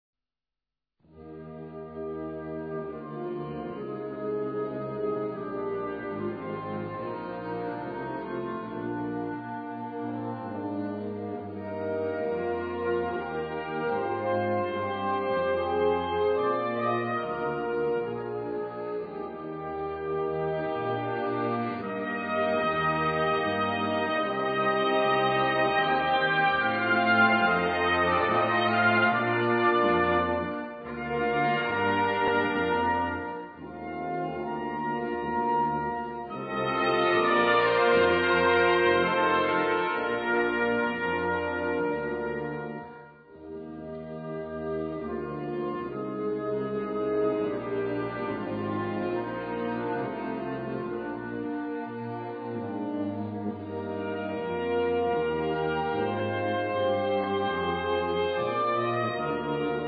Gattung: Konzertante Blasmusik
A4 Besetzung: Blasorchester Zu hören auf